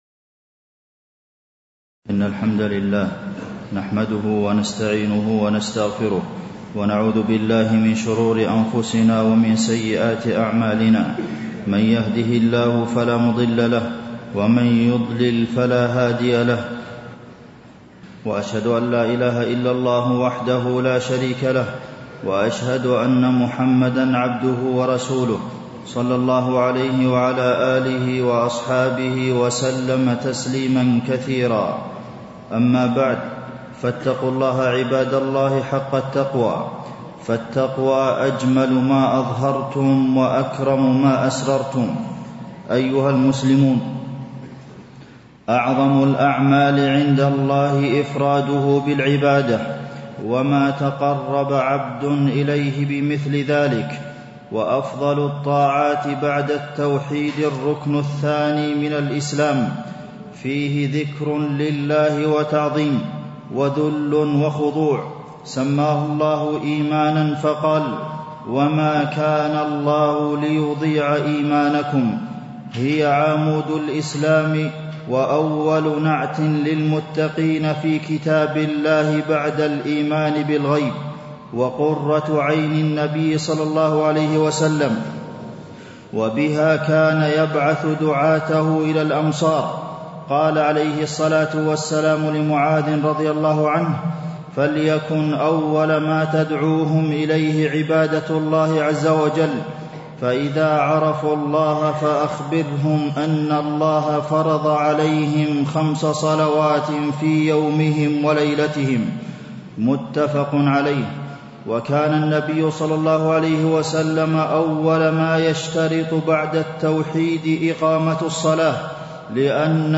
تاريخ النشر ٢٧ جمادى الأولى ١٤٣٥ هـ المكان: المسجد النبوي الشيخ: فضيلة الشيخ د. عبدالمحسن بن محمد القاسم فضيلة الشيخ د. عبدالمحسن بن محمد القاسم منزلة الصلاة في الإسلام